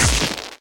spark.ogg